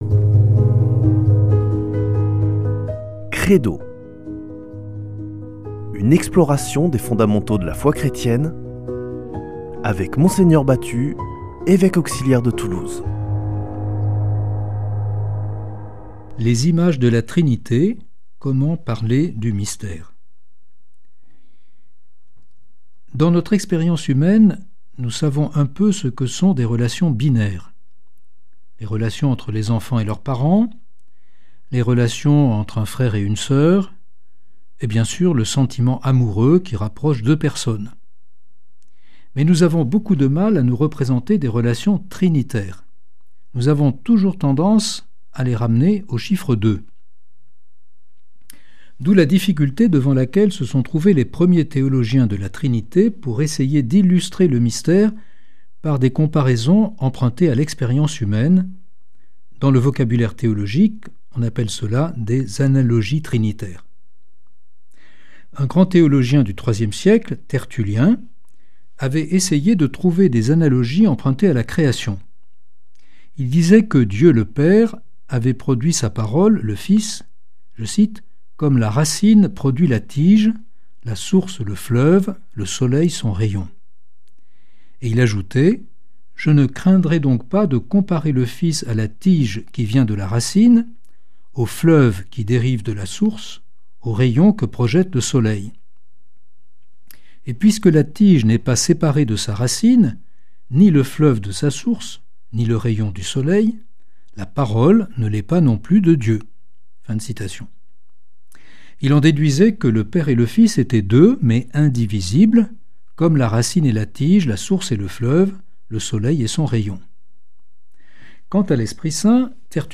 Une émission présentée par Mgr Jean-Pierre Batut Evêque auxiliaire de Toulouse